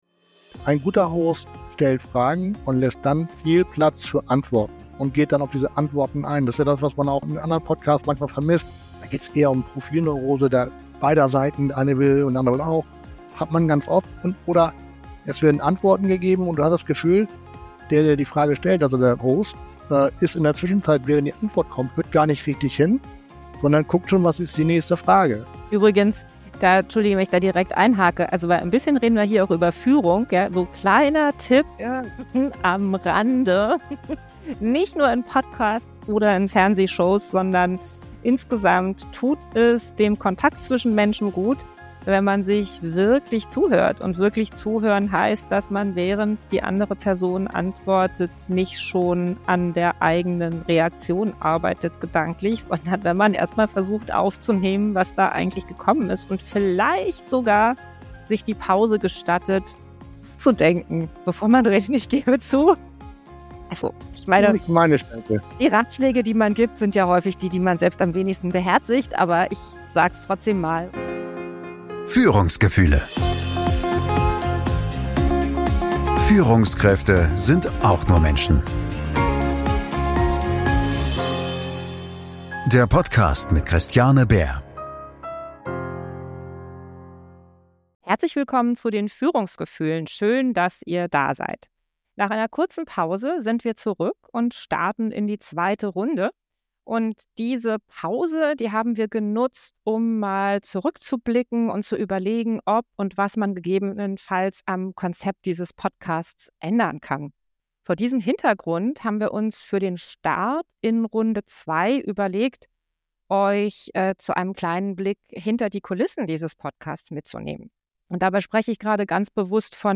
Außerdem gibt es Outtakes, persönliche Reflexionen und eine spannende Frage: Was macht einen Podcast erfolgreich?